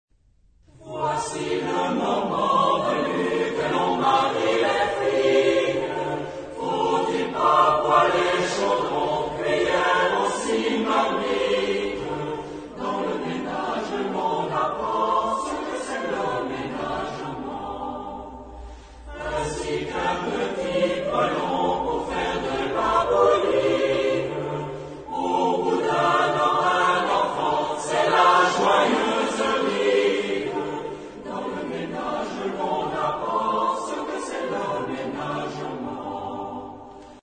Genre-Stil-Form: weltlich ; Volkstümlich
Chorgattung: SATB  (4 gemischter Chor Stimmen )
Tonart(en): D dorisch